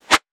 metahunt/weapon_bullet_flyby_22.wav at master
weapon_bullet_flyby_22.wav